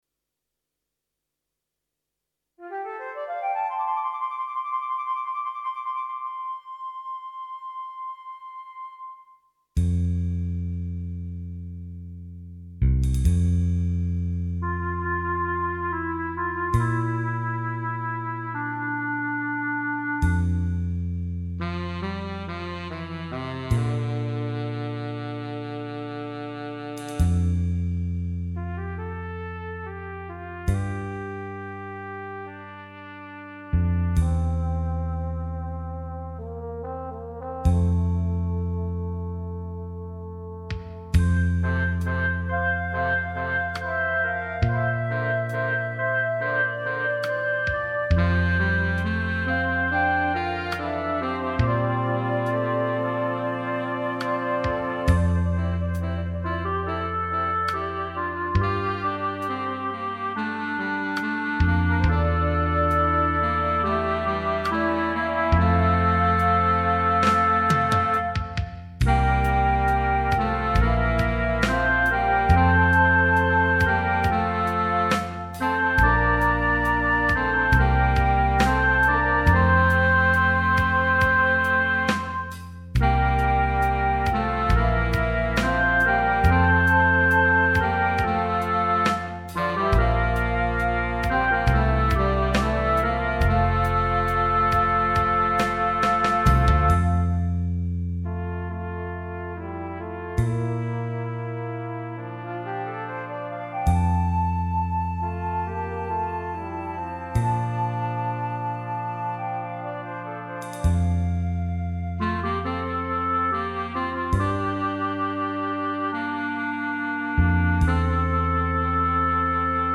minus Piano